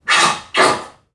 NPC_Creatures_Vocalisations_Robothead [6].wav